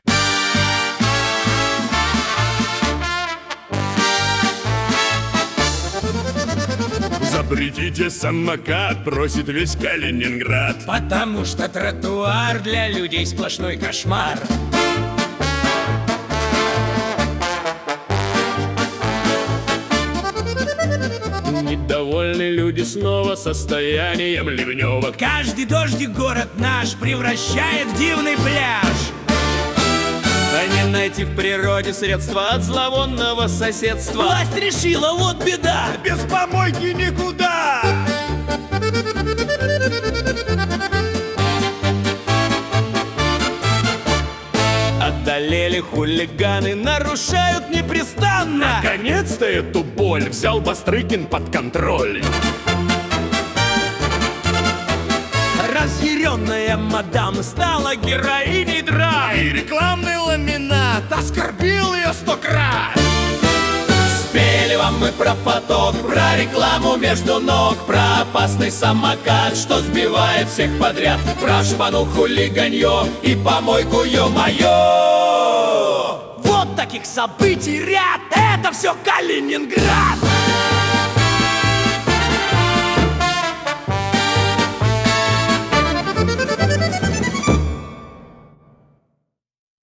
— просит весь Калининград»: представляем музыкальный дайджест новостей за неделю (видео)
Куплеты на злобу дня о главных и важных событиях